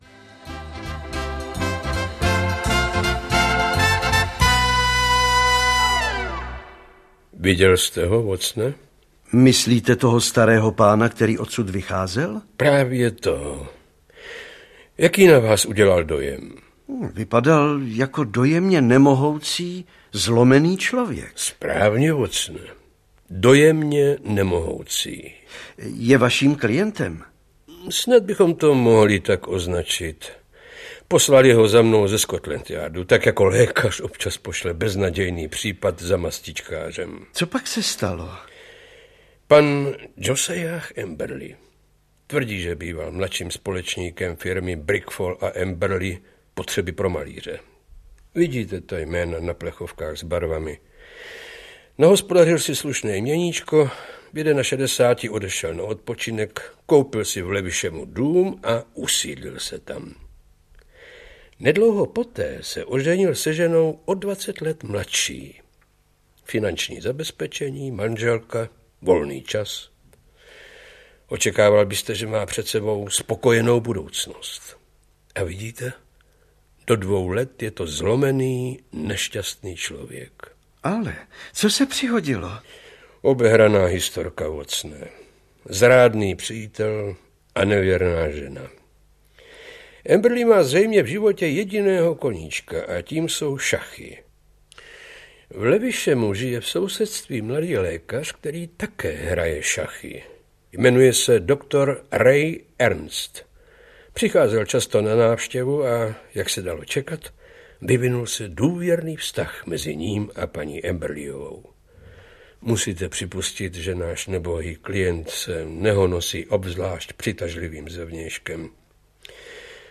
Dvě dramatizace povídek Sira A. C. Doyla z archivu brněnského studia Českého rozhlasu.